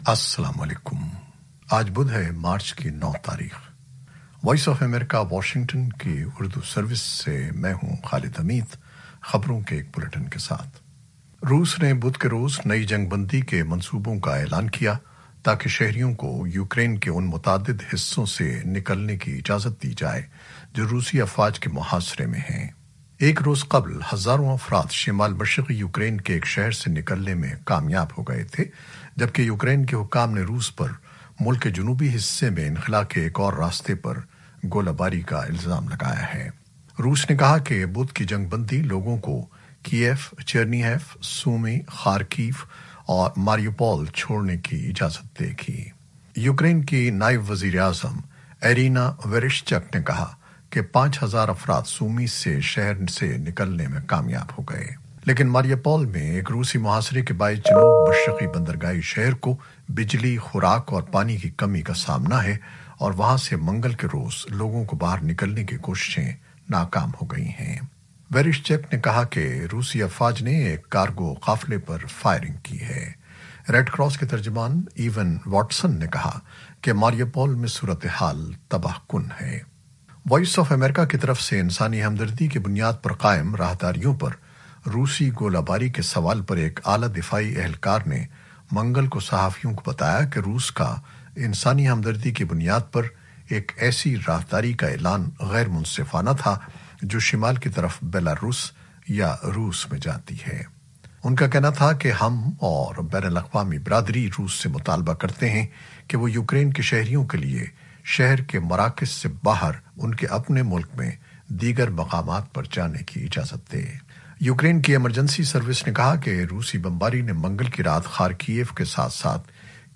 نیوز بلیٹن 2021-09-03